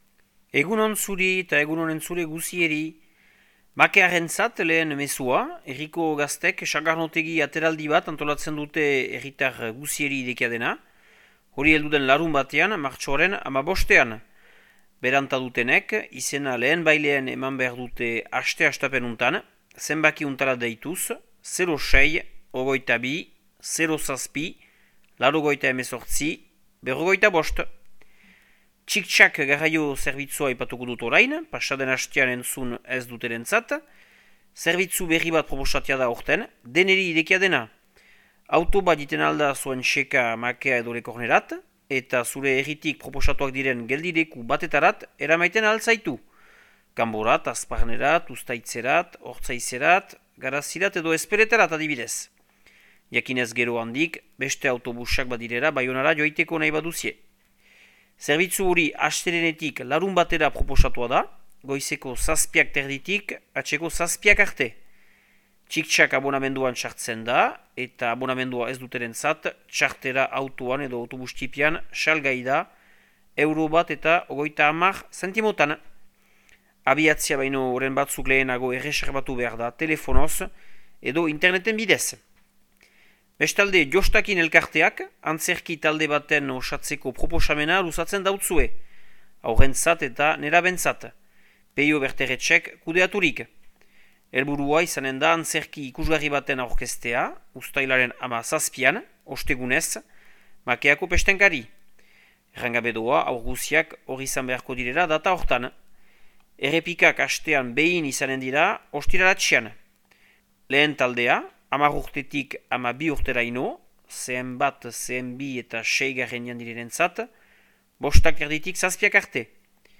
Martxoaren 10eko Makea eta Lekorneko berriak